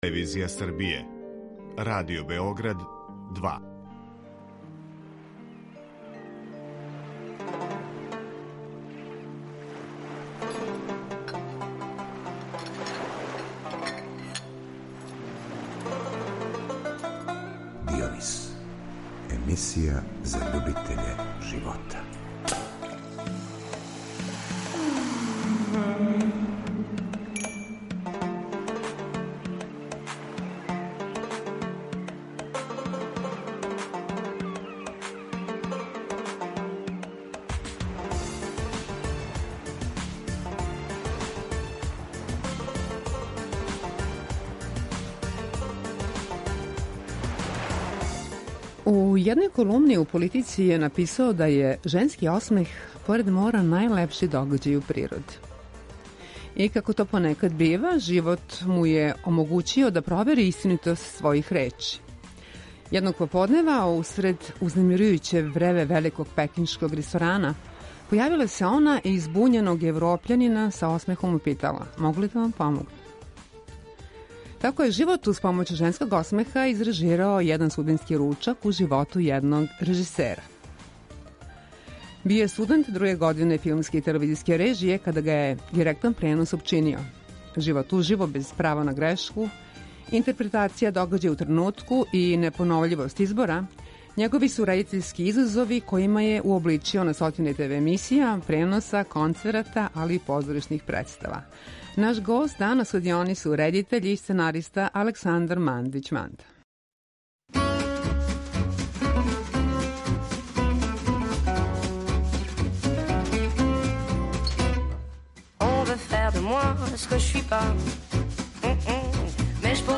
Живот уживо, без права на грешку, интерпретација догађаја у тренутку и непоновљивост избора његови су редитељски изазови којима је уобличио на стотине ТВ емисија, преноса, концерата, али и позоришних представа. Наш гост у Дионису редитељ и сценариста Александар Мандић Манда .